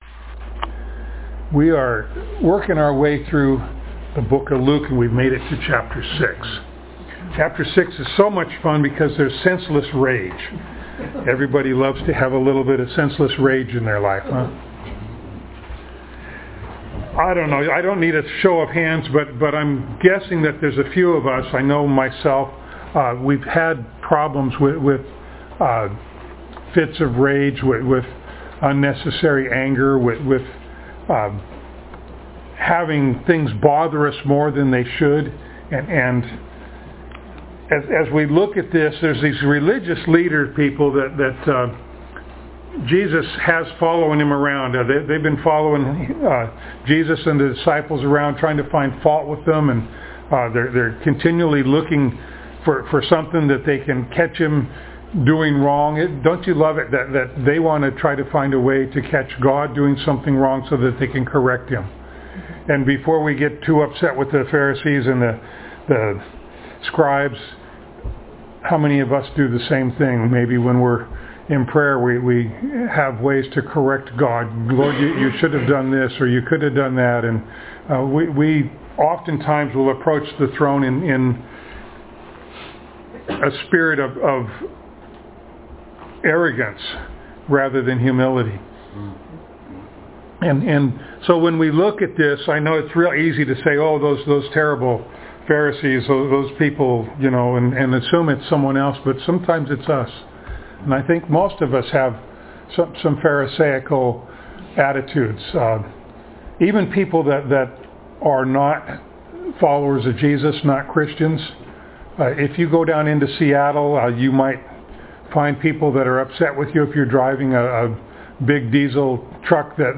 Luke Passage: Luke 6:1-11, 1 Samuel 21:1-9, James 1:19-20, Isaiah 64:6, Romans 6:12-23 Service Type: Sunday Morning